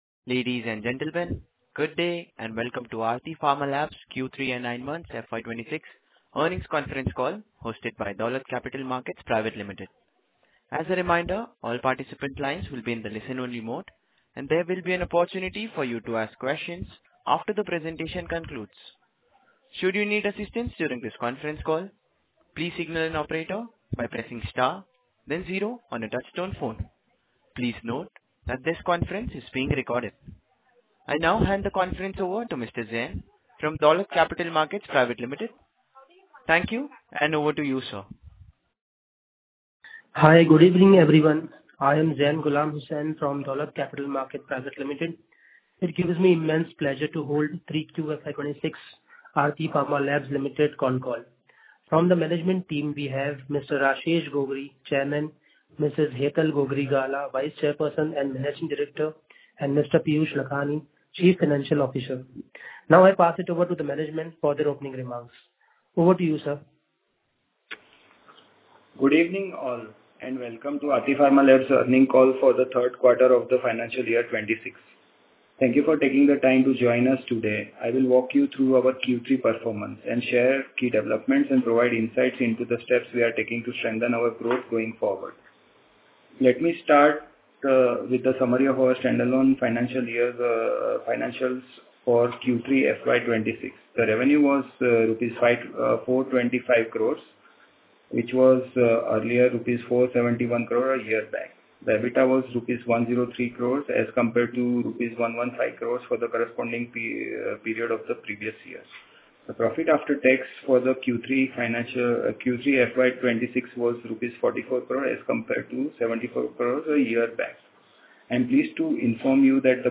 q3-fy-26-earnings-concall.mp3